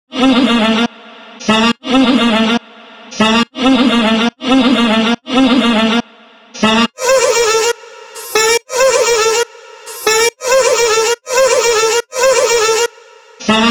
From soulful vocal chops and hypnotic melodies to groovy basslines and punchy drum loops, each sample captures the essence of Ritviz’s unique style.
Gully-Loops-Desi-Riddim-Drop-Loop-BPM-140-F-Min.wav